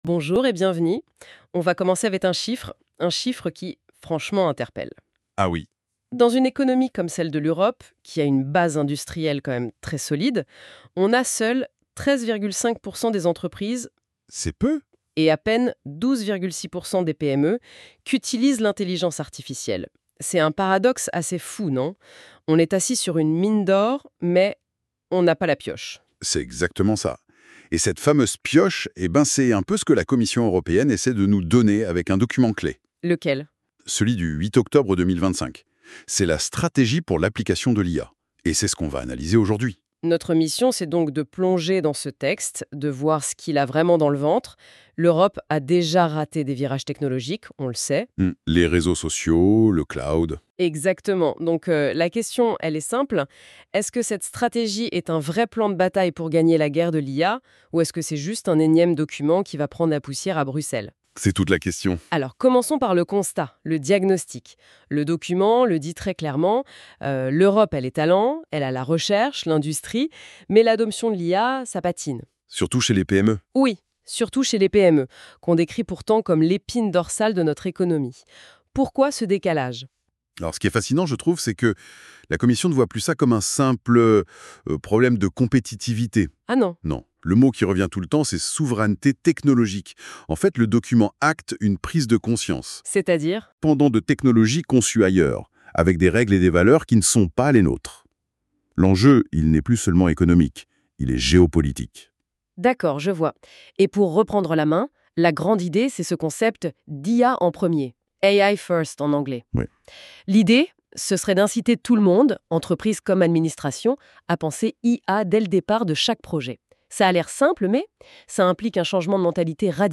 [!Success] Ecoutez le débat /uploads/default/original/2X/3/352cc882e8cd82c832e327f313aff1773c957ef6.mp3